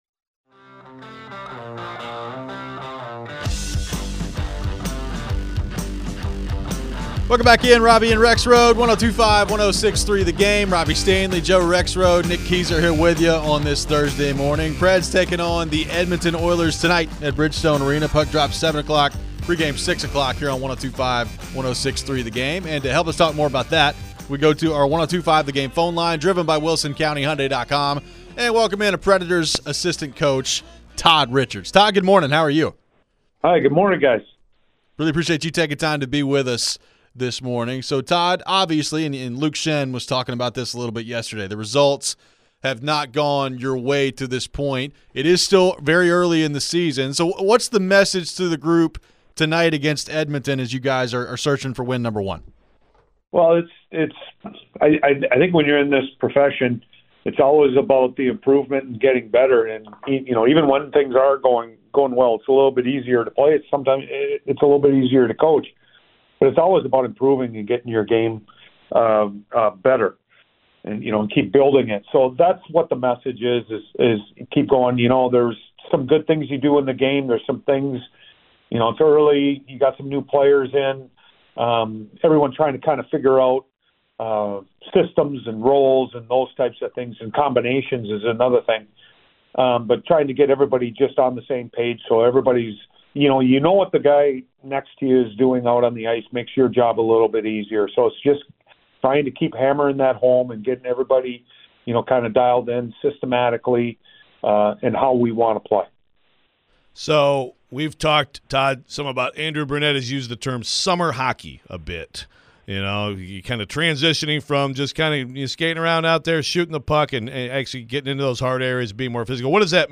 Todd Richards Interview (10-17-24)
Nashville Predators assistant coach Todd Richards joined the show to preview tonight's matchup against the Edmonton Oilers. What can the Preds do to pick up their first win of the season?